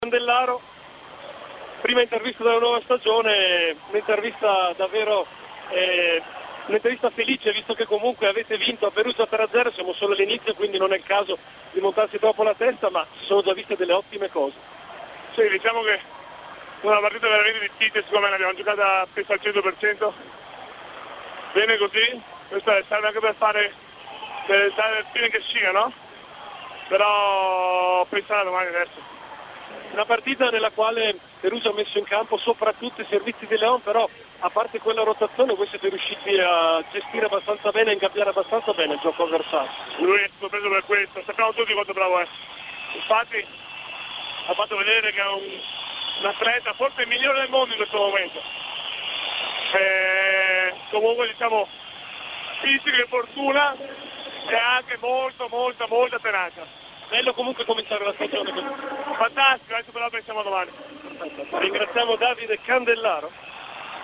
Mp3 interview